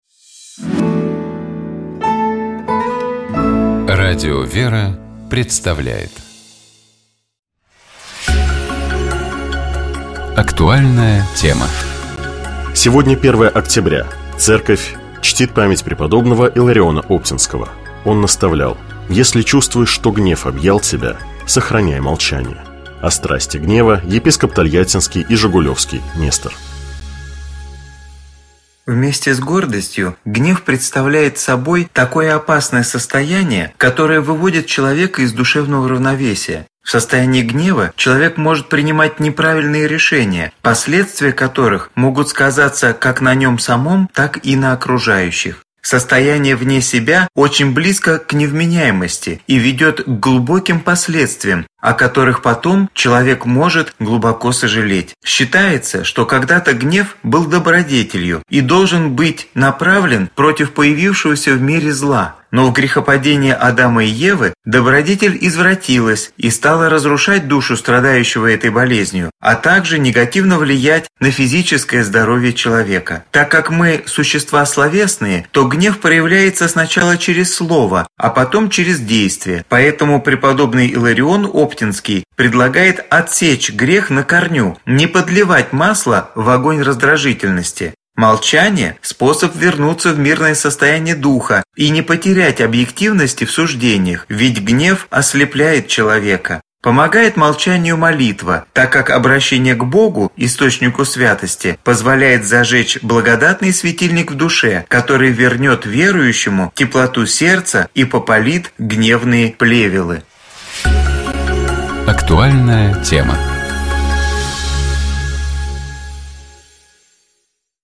О страсти гнева, — епископ Тольяттинский и Жигулёвский Нестор.